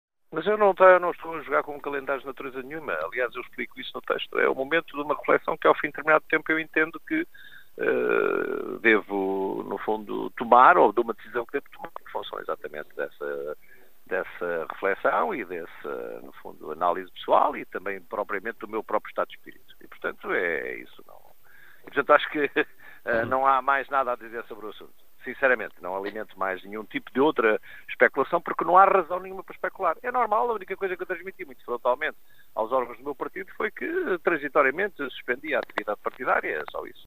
O socialista Jorge Fão em declarações à Rádio Caminha.